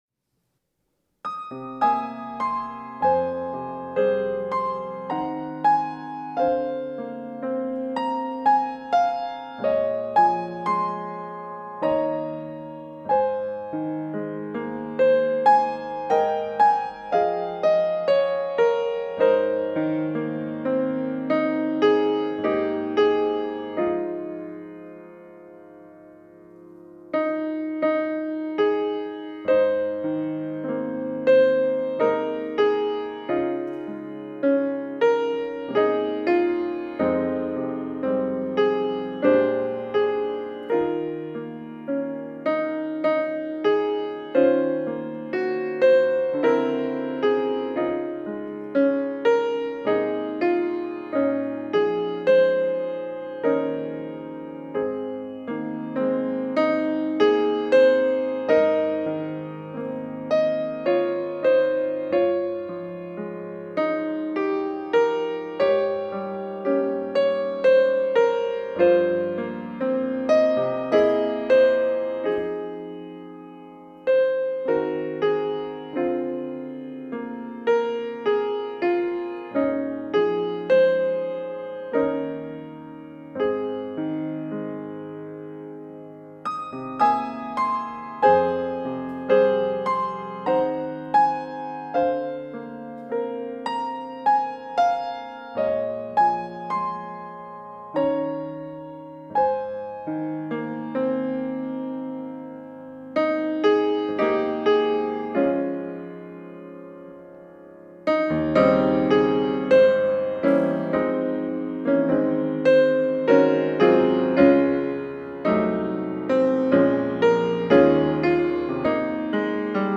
특송과 특주 - 저 높은 곳을 향하여
천안중앙교회